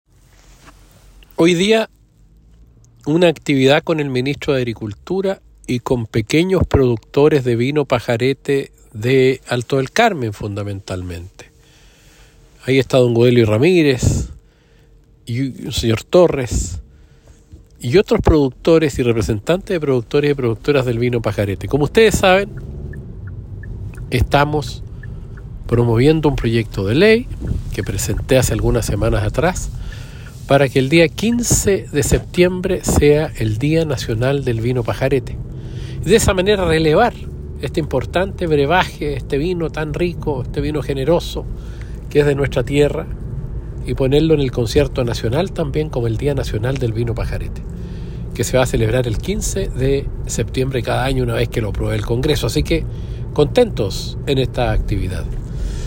Diputado Jaime Mulet, quien ahonda en la presentación junto al ministro de Agricultura del Proyecto de Ley que declara el día 15 de septiembre como el día nacional del vino pajarete.